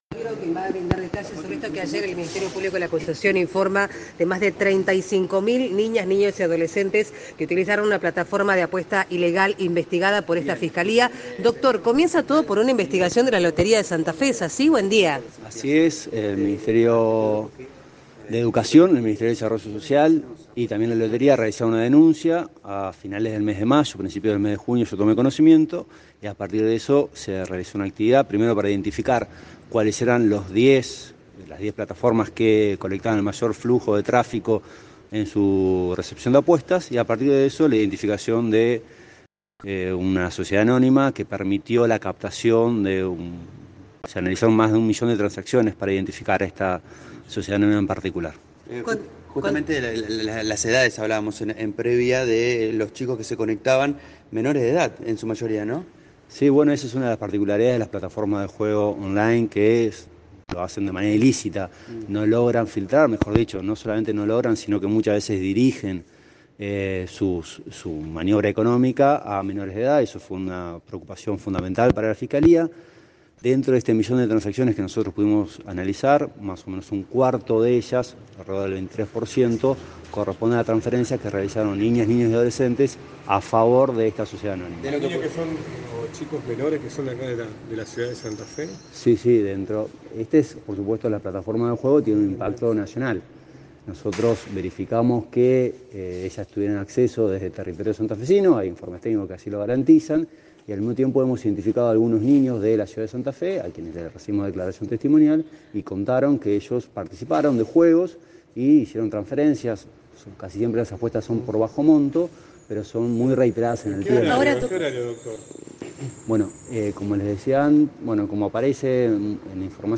Conferencia de prensa del Fiscal Nigro
conferencia-fiscal-nigro.mp3